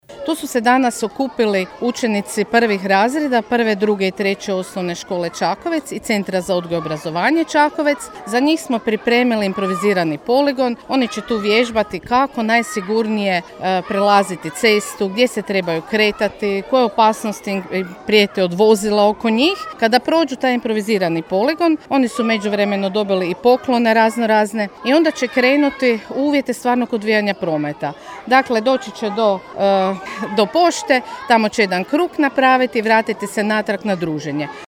U sklopu nacionalne akcije “Poštujte naše znakove” , na Trgu Republike u Čakovcu održana je akcija “Sigurno i vješto u prometu”.